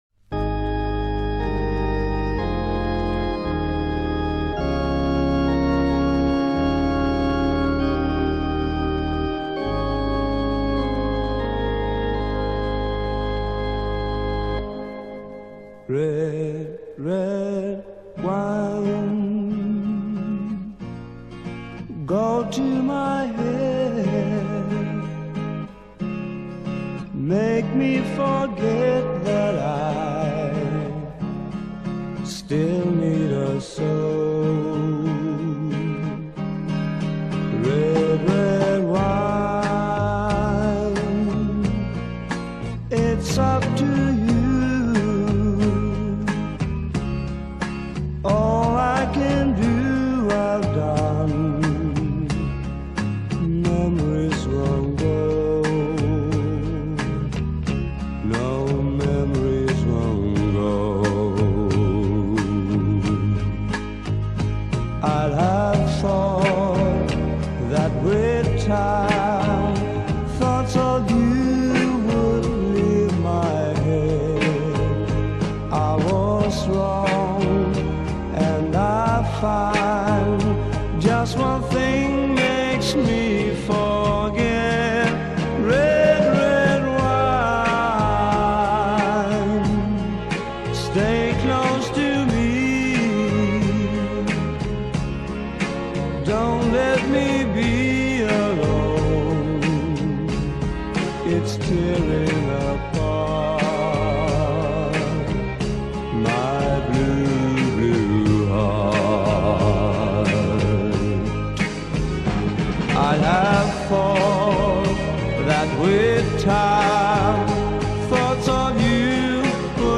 Это Шведская поп группа из далеких 60 годов...